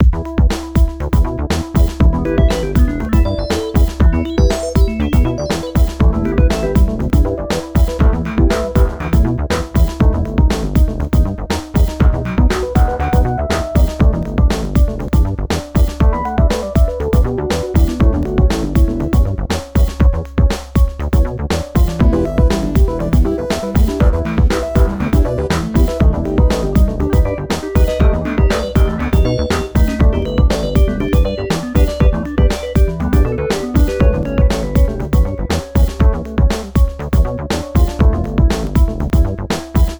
Tipo videojuego 1 (bucle)
videojuego
repetitivo
rítmico
sintetizador
Sonidos: Música